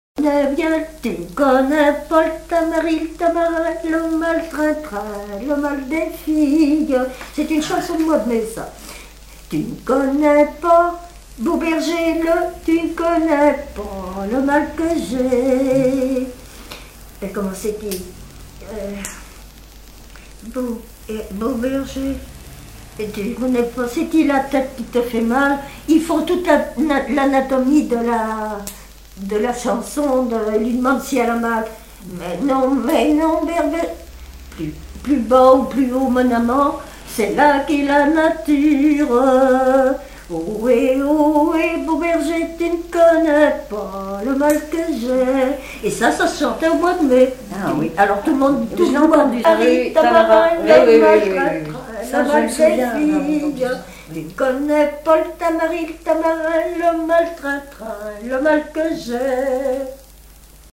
Mémoires et Patrimoines vivants - RaddO est une base de données d'archives iconographiques et sonores.
Chansons et commentaires
Pièce musicale inédite